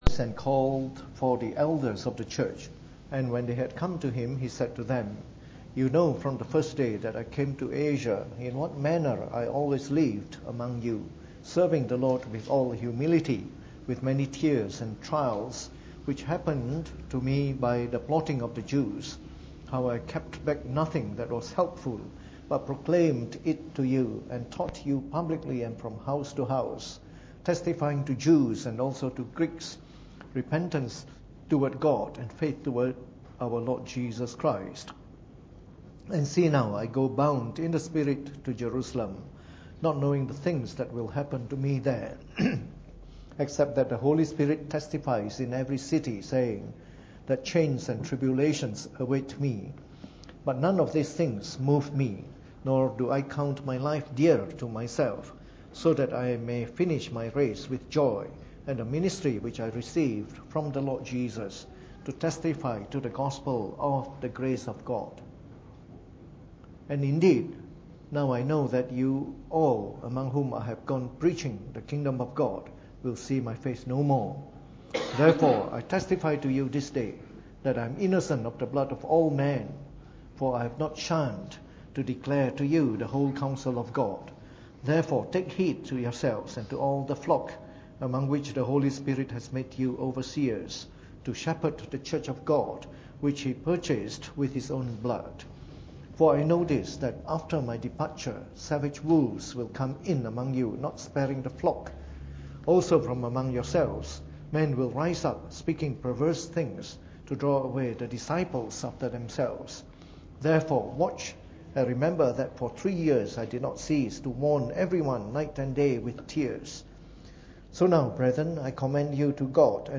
Preached on the 1st of November 2017 during the Bible Study.